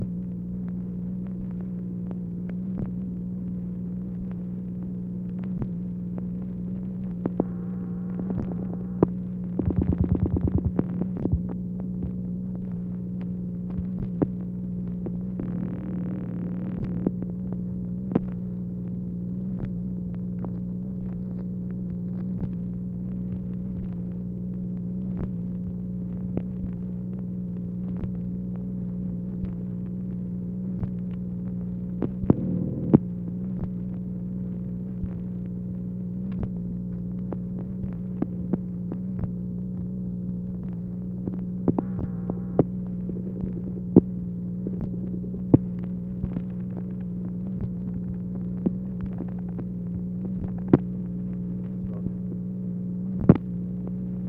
MACHINE NOISE, January 16, 1964
Secret White House Tapes | Lyndon B. Johnson Presidency